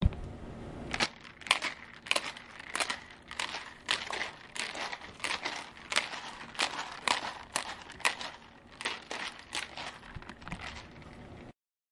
努纳维克 " 雪块冰屋的砖头放下来嘎吱嘎吱或打或拍（在低位工作）。
描述：雪块雪屋冰砖放下紧缩或打或拍（低水平工作）
标签： 冰屋 砌块 多孔砖 下来 紧缩
声道立体声